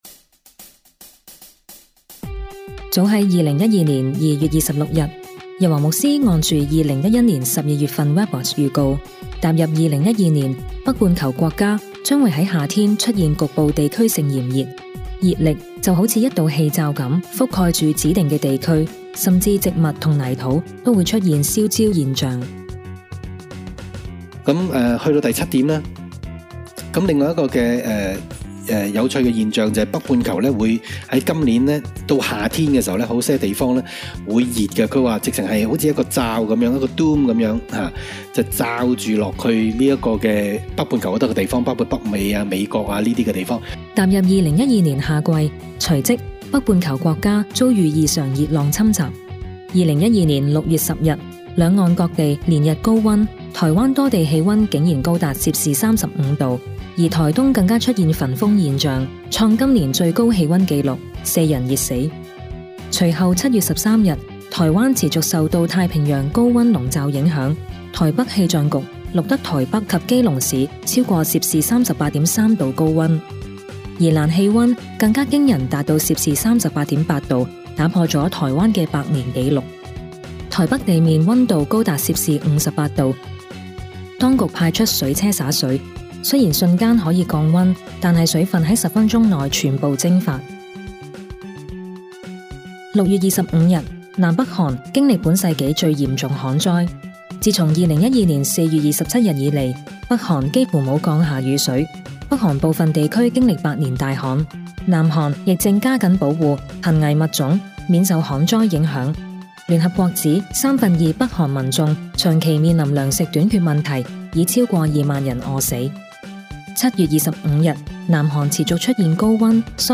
請按上面可觀看或下載2012榮耀盼望主日信息筆記 Vol. 131